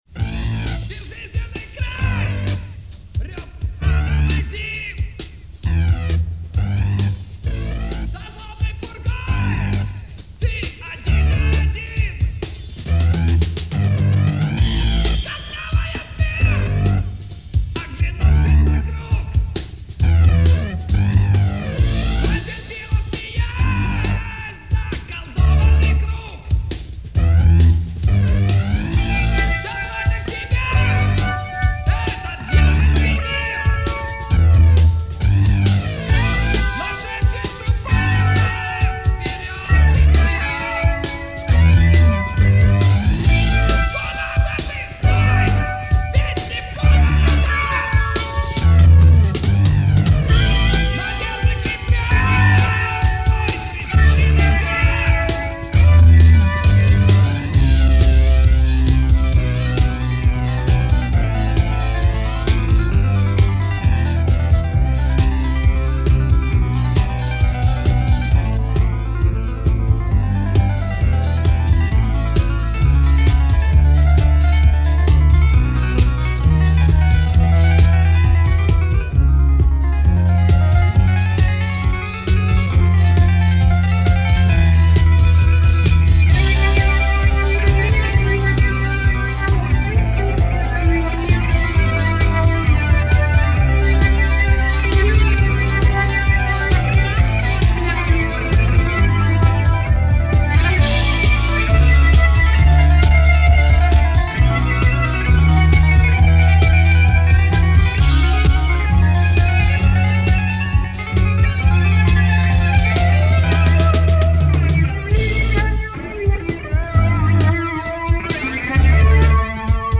Жанр: Рок
Был записан на магнитных носителях и содержал восемь песен.